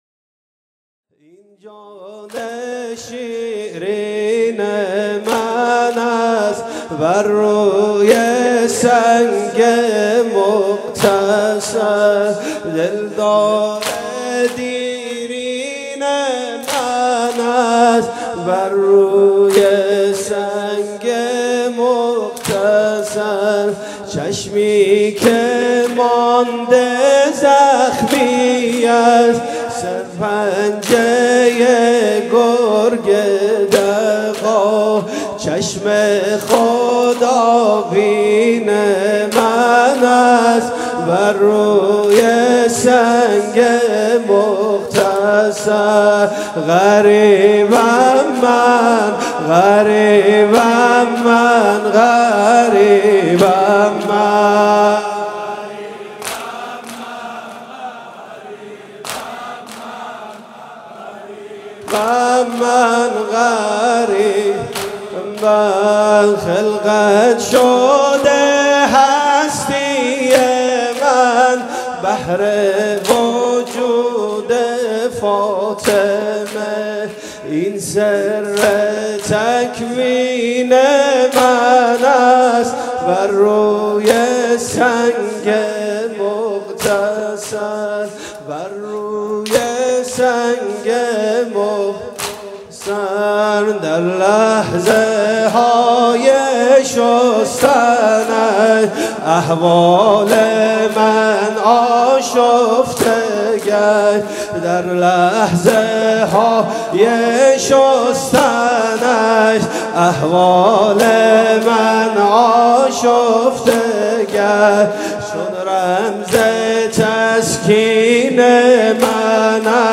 1 اسفند 96 - هیئت شبان القاسم - این جان شیرین من است
فاطمیه